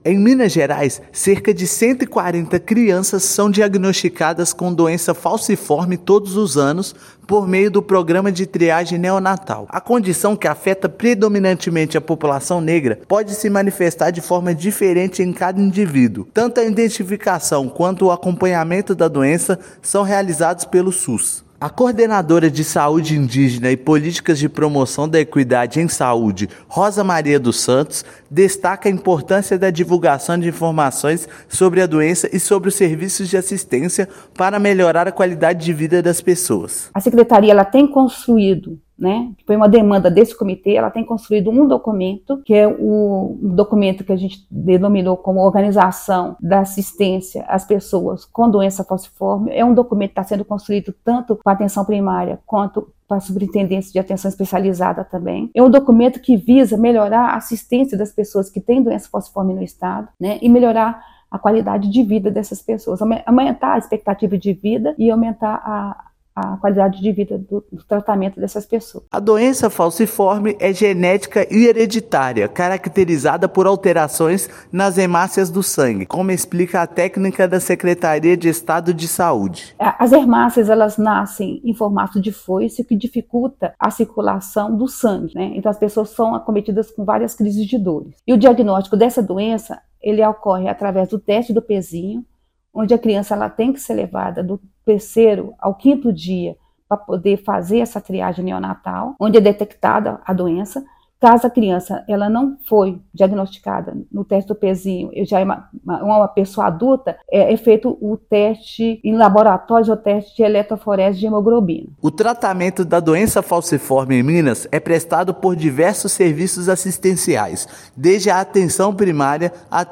Diagnóstico e tratamento para esta condição, que afeta principalmente a população negra, estão disponíveis pelo SUS em todo o estado. Ouça matéria de rádio.
Rádio_-_Matéria_doença_falciforme.mp3